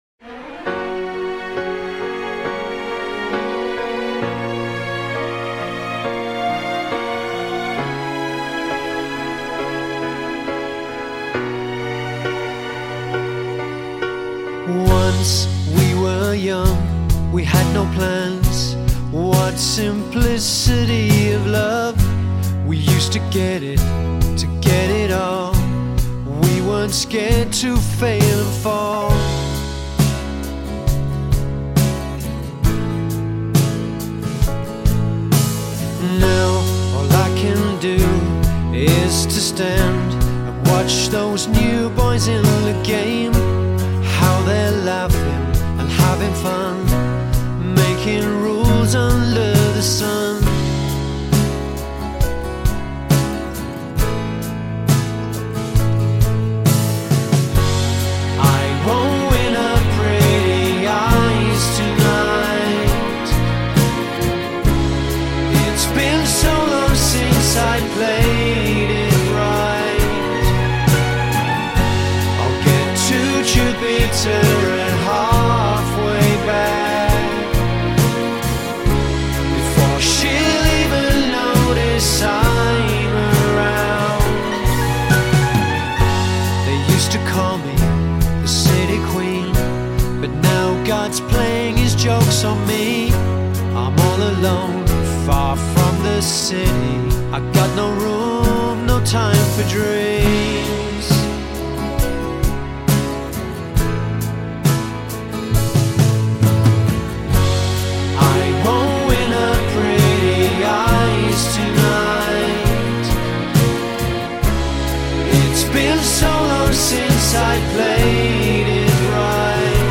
which more orchestral pop than guitar rock.
dreamy, orchestral pop sound